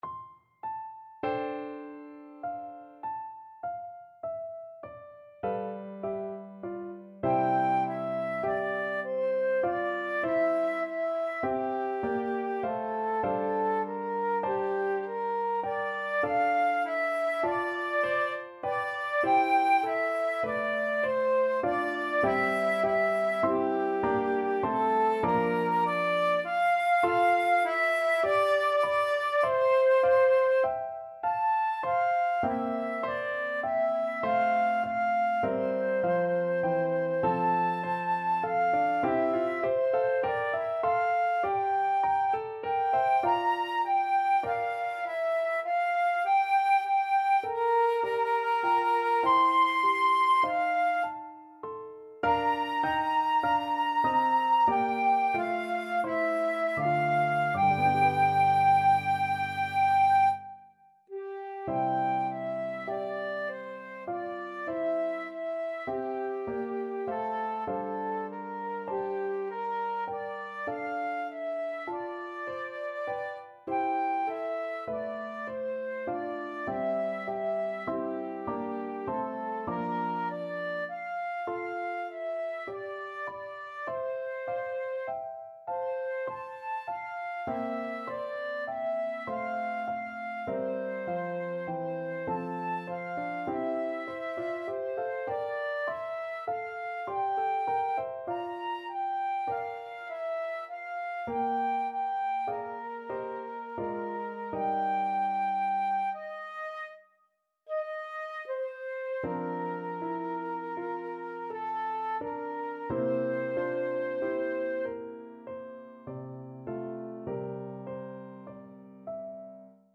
5/4 (View more 5/4 Music)
Classical (View more Classical Flute Music)